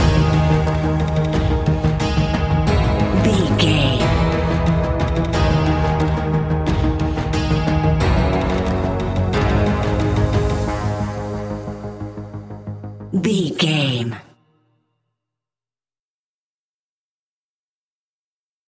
Aeolian/Minor
G#
ominous
dark
eerie
drums
percussion
synthesiser
ticking
electronic music